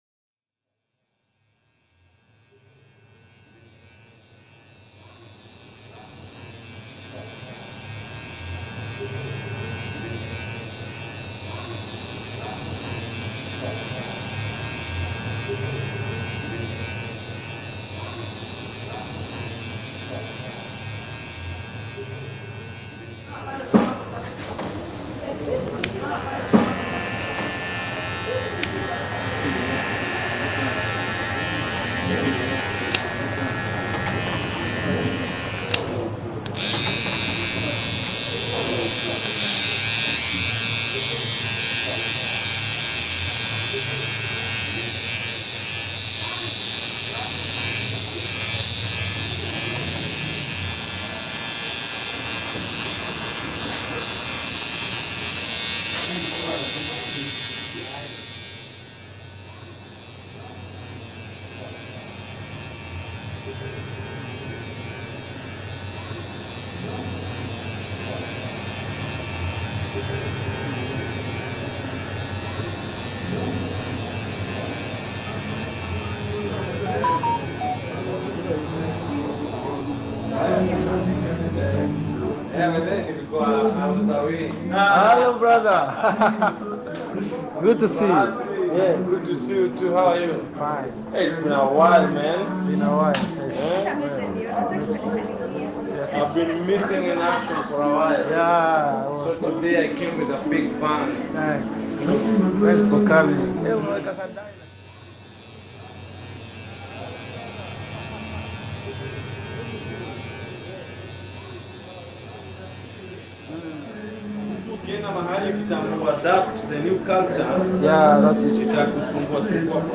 Nairobi barber shop reimagined